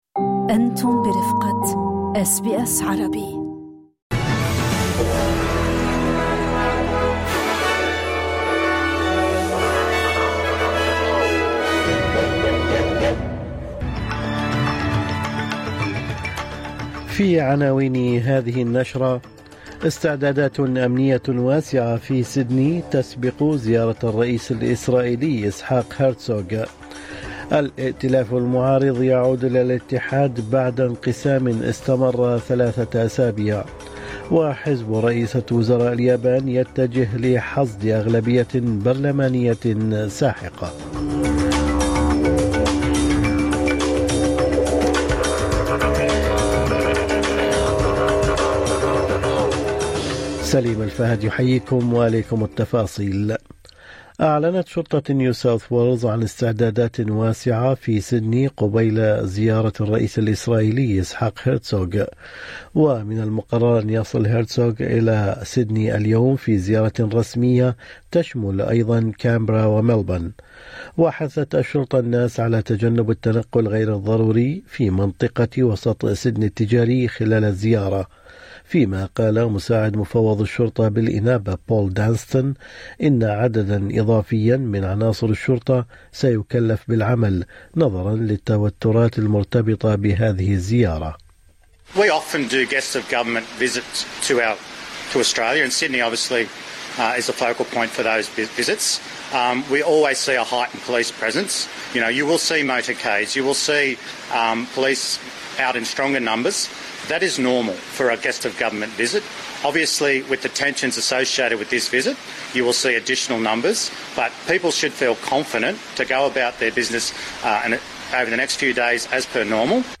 نشرة أخبار الصباح 9/2/2026